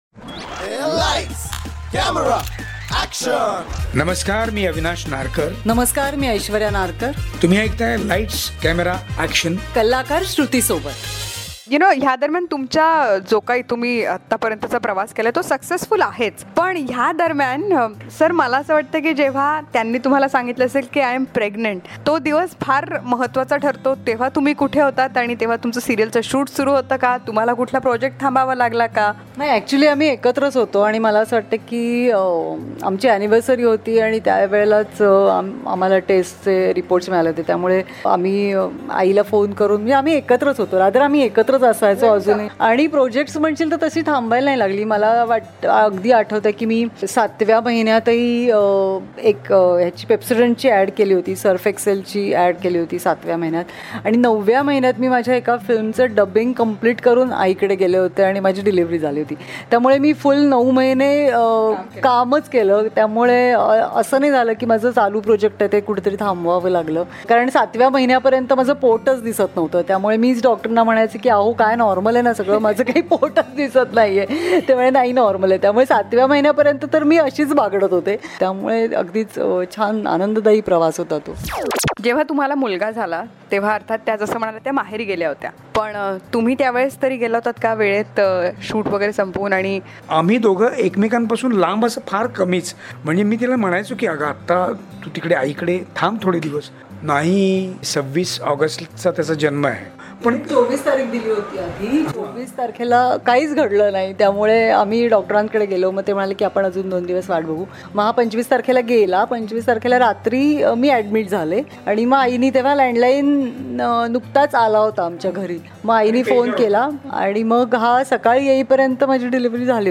Love is a beautiful journey where every step is a destination and every moment eternity .. Listen to this podcast as the cutest romantic couple talks about their journey of life exclusively on Lights Camera Action.